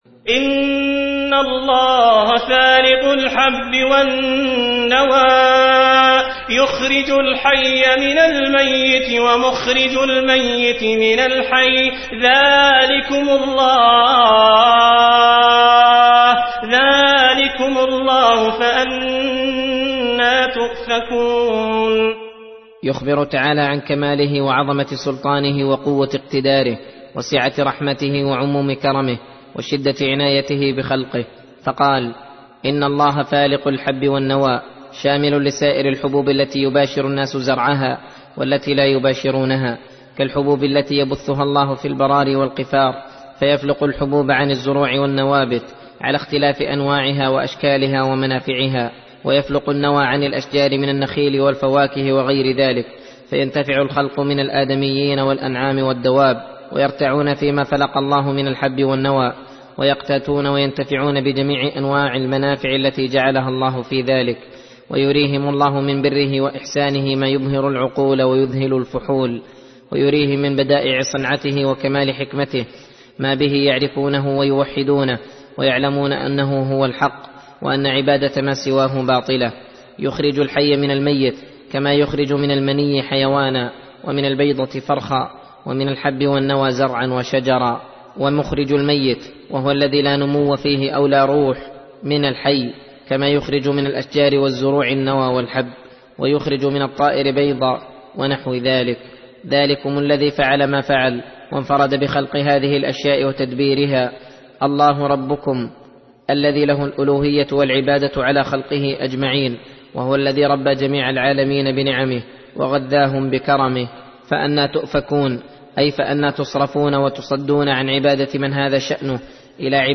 درس (30) : تفسير سورة الأنعام : (95-108)